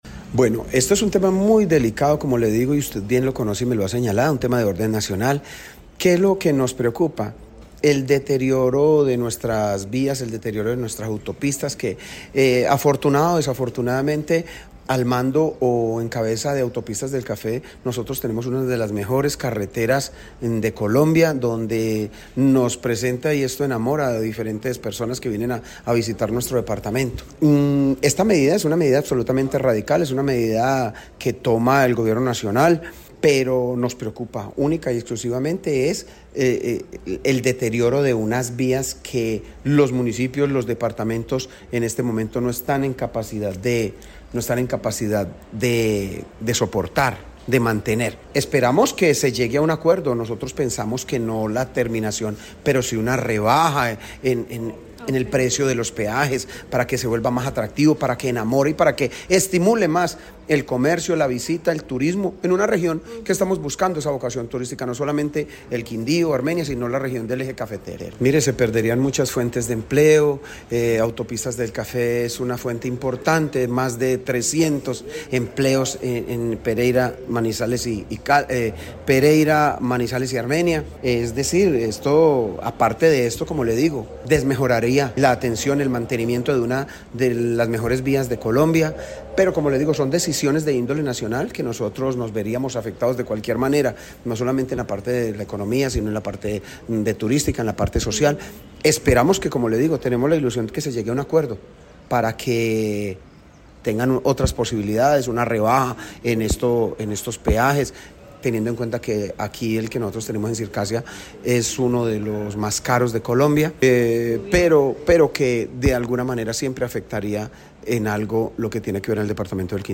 En diálogo con 6AM/W de Caracol Radio Armenia, expresó su preocupación por el mantenimiento vial y el impacto económico en el Eje Cafetero.
Alcalde de Armenia, James Padilla García